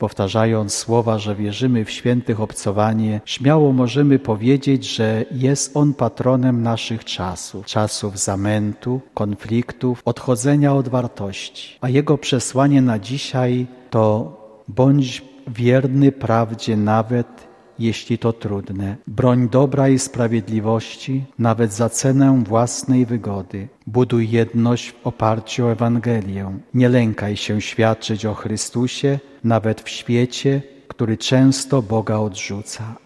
W pierwszym dniu uroczystości odprawiona została Msza św. pod przewodnictwem arcybiskupa Mieczysława Mokrzyckiego, metropolity lwowskiego.
Arcybiskup podczas homilii zwrócił uwagę na to jak aktualne w dzisiejszych czasach jest przesłanie św Stanisława.
4msza1.mp3